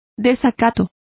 Complete with pronunciation of the translation of contempt.